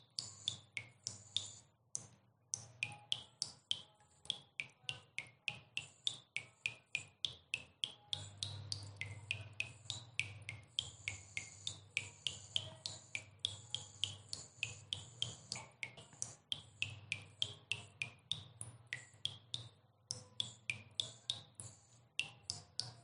Water Dropping from Tap Sound
Focused sound of water droplets falling from a tap, capturing a slow and repetitive dripping pattern. Each drop is clearly defined with subtle echo and spacing, creating a rhythmic and sometimes tense atmosphere. The recording reflects a leaking faucet or partially closed tap, making it useful for realistic household scenes.
The clean separation of droplets enhances clarity and realism.
Category: Water Sounds · All Soundopedia recordings are 100% authentic — no AI, no synthesis.
water-dropping-from-tap-sound